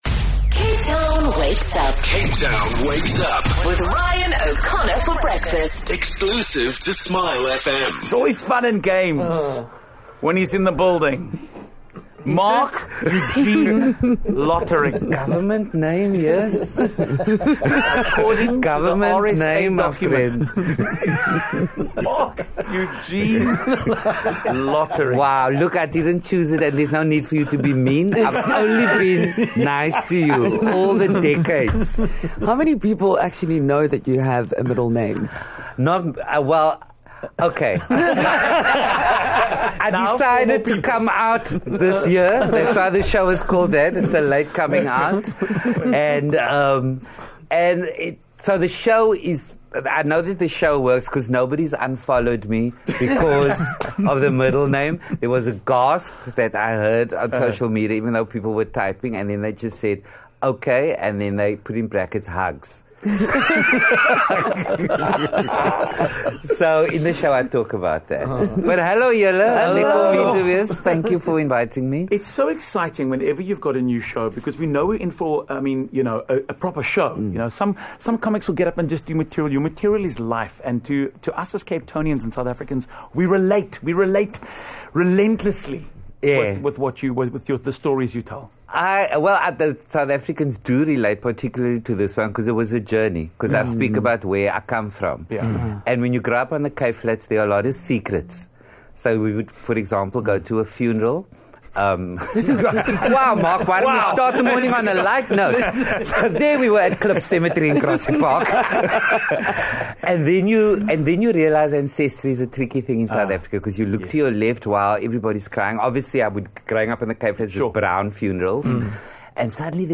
27 Mar Marc Lottering full interview on Smile FM